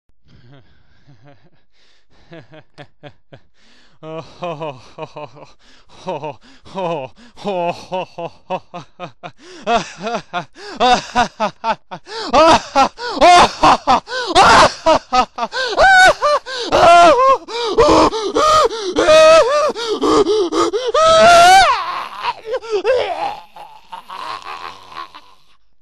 Oké, die man vindt de slogan bijzonder geslaagd dacht ik relativerend, maar toen zijn deur dicht was kon ik hem doorheen het hout steeds harder en harder horen lachen tot het uiteindelijk uitgroeide tot een onvoorstelbaar gebulder dat zo hevig en vervormd was dat
hahaHAHA.wma